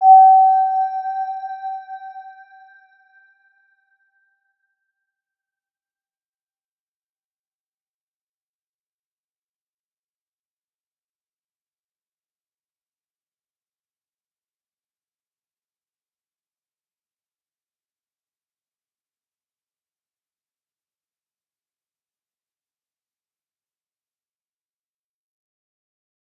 Round-Bell-G5-p.wav